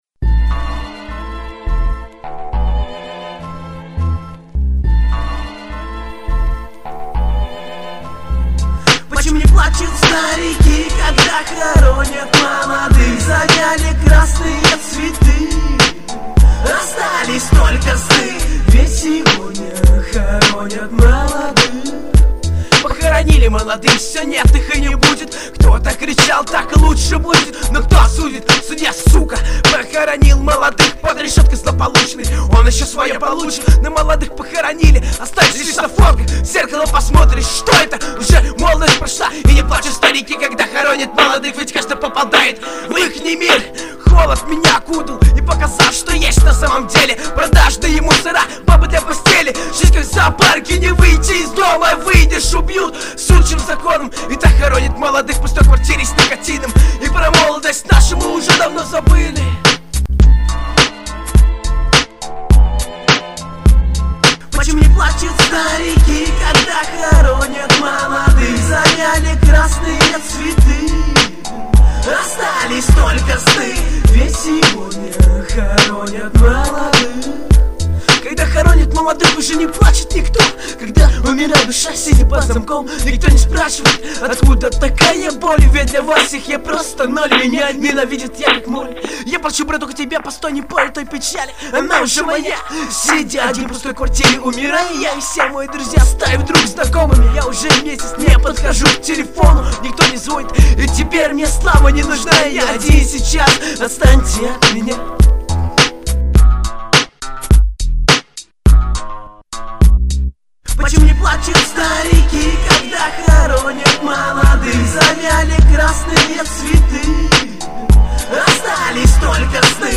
молодая рэп группа
Трэки:, 2005\21 Рэп